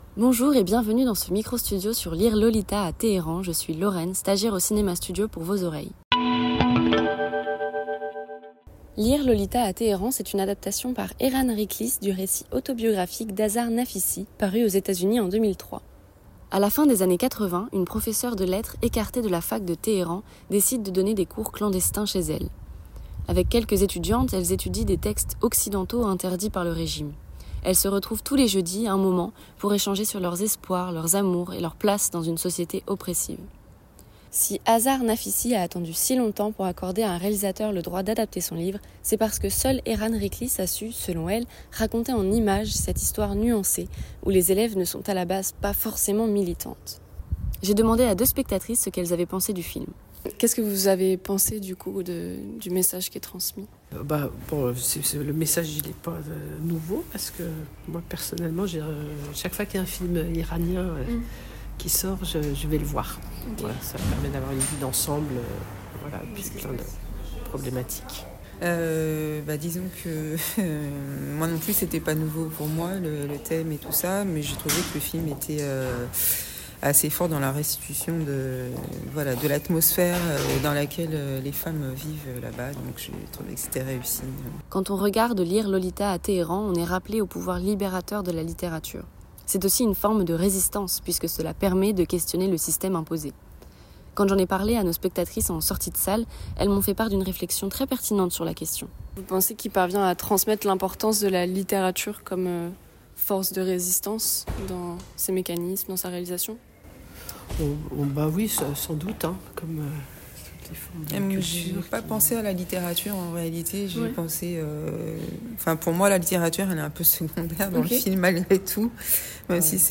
Bande annonce